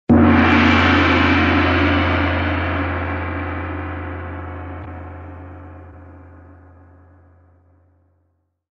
Loud Gong Sound Effect Free Download
Loud Gong